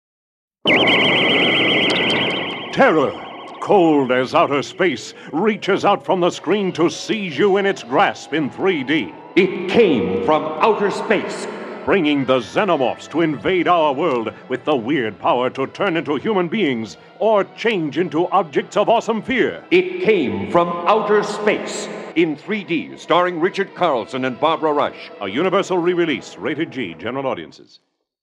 1972 3D Radio Spots